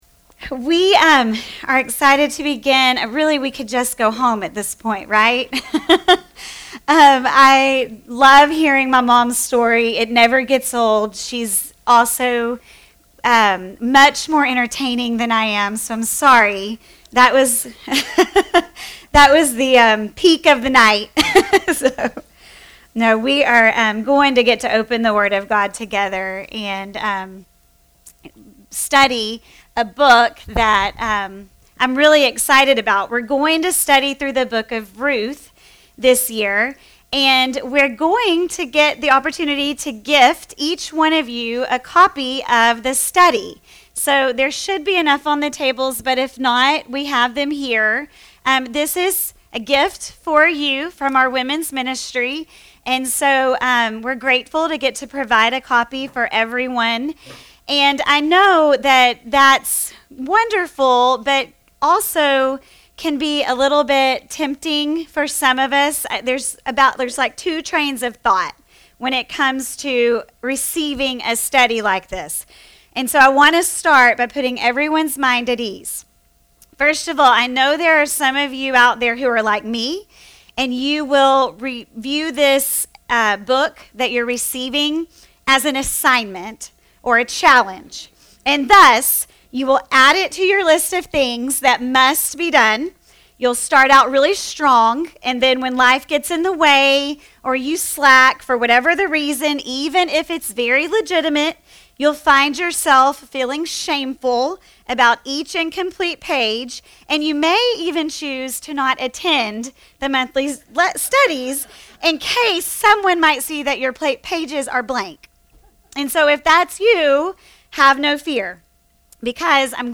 Keltys Women’s Ministry Bible Study “Ruth